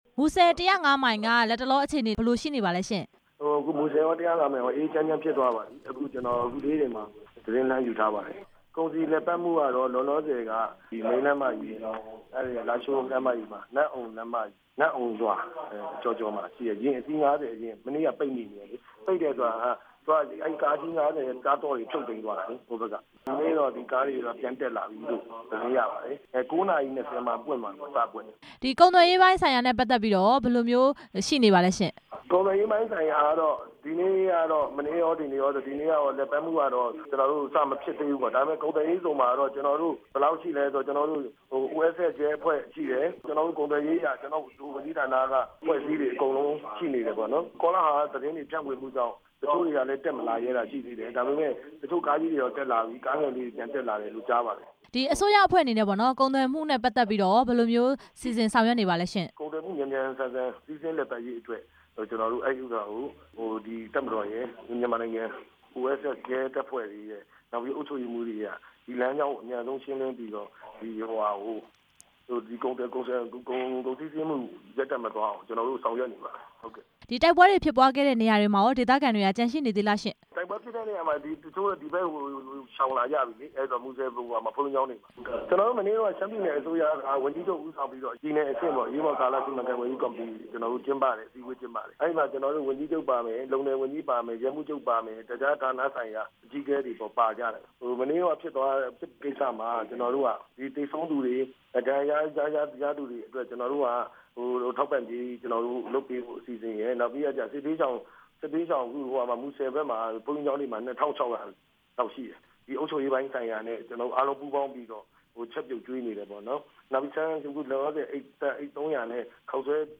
ရှမ်းပြည်နယ် မူဆယ် ၁၀၅ မိုင်ကုန်သွယ်ရေးစခန်းမှာ ကုန်စည်ပြန်လည်စီးဆင်းနိုင်ဖို့အတွက် ပြည်နယ်အစိုးရ၊ ရဲတပ်ဖွဲ့ နဲ့ အစိုးရတပ်တို့ ပူးပေါင်းဆောင်ရွက်ပြီး လမ်းကြောင်းရှင်းလင်းရေးတွေ ဆောင်ရွက်နေတယ်လို့ ရှမ်းပြည်နယ် စီမံကိန်းနဲ့ စီးပွားရေးဝန်ကြီး ဦးစိုးညွန့်လွင် က ပြောပါတယ်။